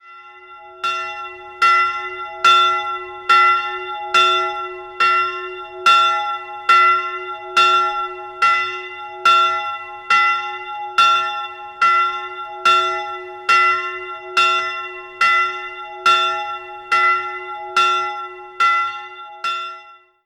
Die kleine Filialkirche besitzt einen freistehenden, in Form eines Kreuzes gestaltete Holz-Glockenträger. Einzelglocke e'' Die Glocke wurde 1872 von Eduard Becker in Ingolstadt gegossen.